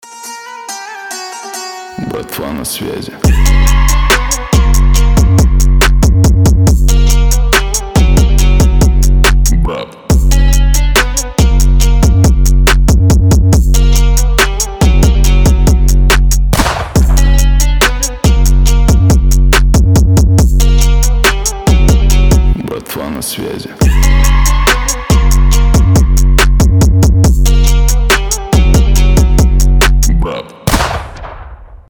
восточные
выстрел
Четкий трэпчик на братюнь